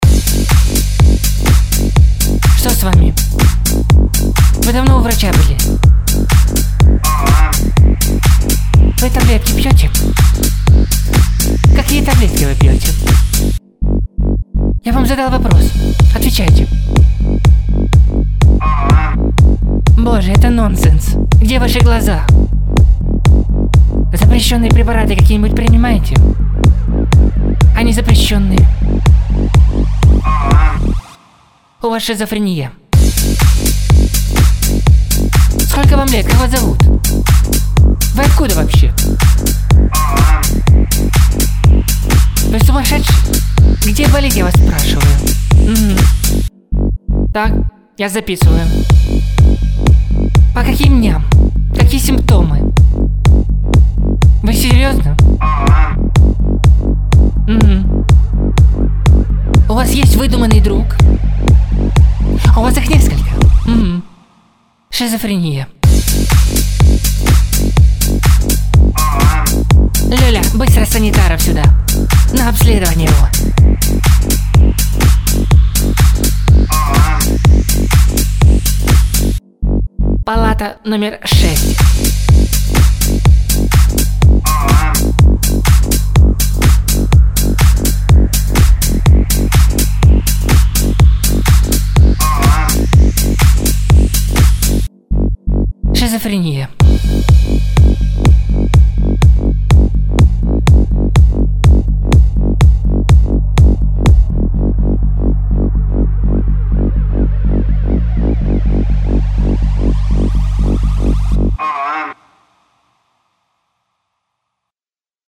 Класный песня супер бассы❤🙌